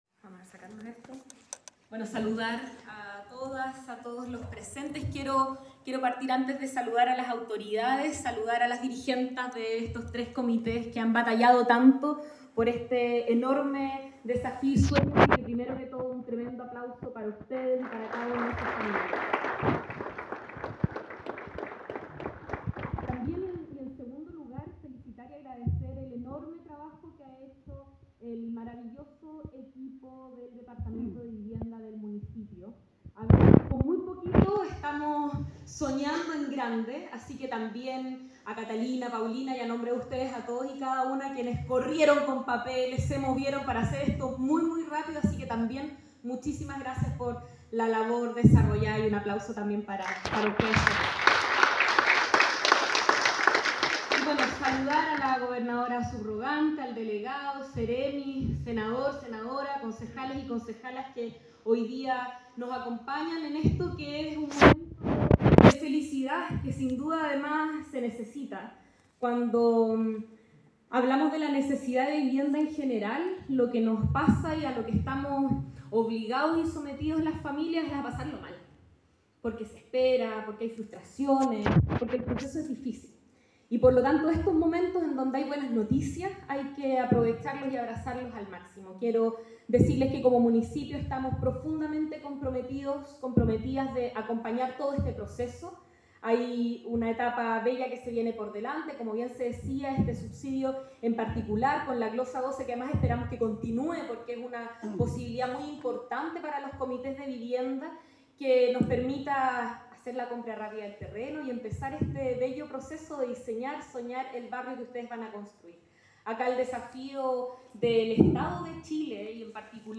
La actividad que se desarrolló en el Teatro Lord Cochrane contó con la presencia del Delegado Presidencial, Cesar Asenjo; el senador, Alfonso de Urresti; la senadora electa, María José Gatica; la Gobernadora(s), Paz de La Maza; el Seremi de Vivienda y Urbanismo, Ignacio Vidal; la Alcaldesa de Valdivia, Carla Amtmann; junto a las dirigentes y beneficiarios.
alcaldesa, Carla amtmann
alcaldesa-Carla-amtmann.aac